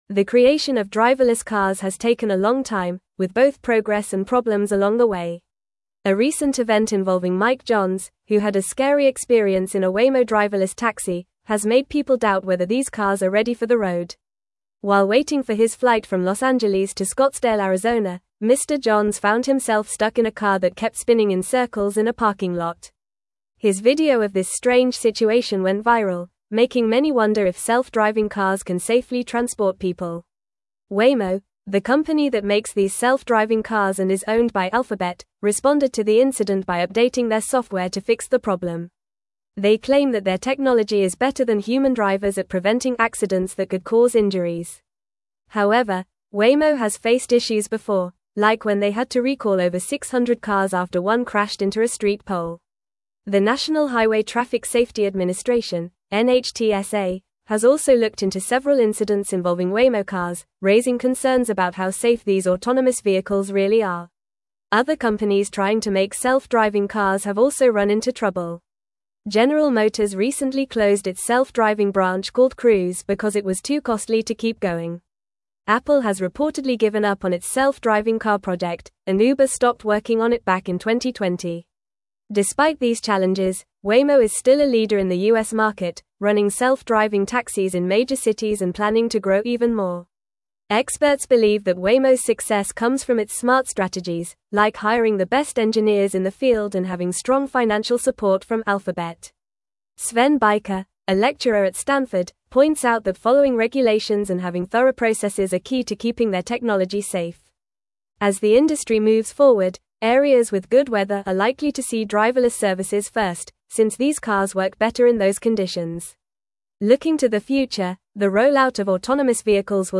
Fast
English-Newsroom-Upper-Intermediate-FAST-Reading-Challenges-Persist-in-the-Adoption-of-Driverless-Vehicles.mp3